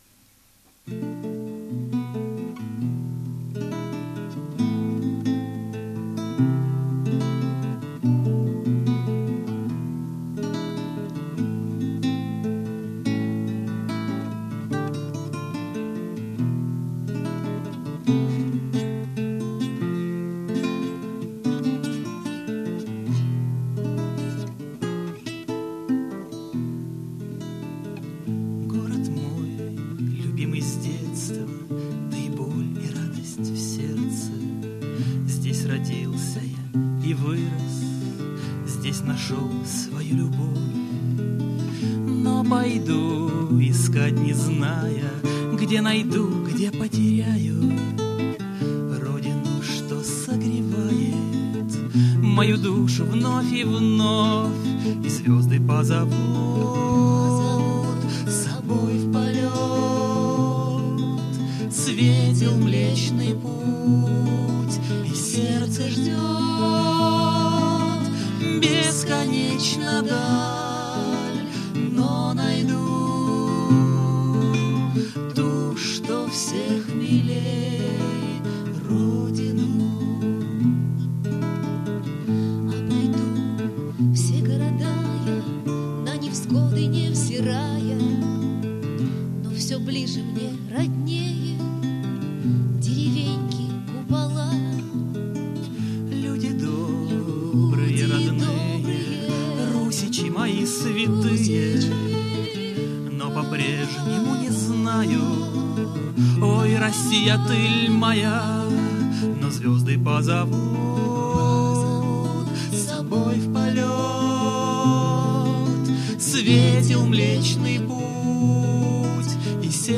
Концерт к 33-х летию